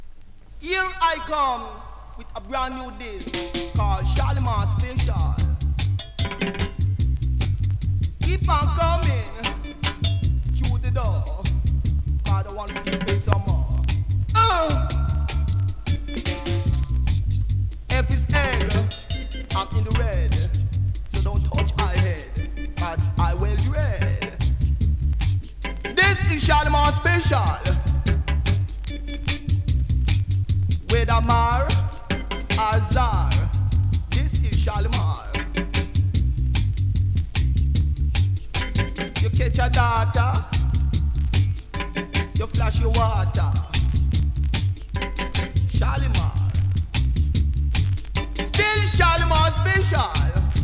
ホーム > 2018 NEW IN!!SKA〜REGGAE!!
スリキズ、ノイズかなり少なめの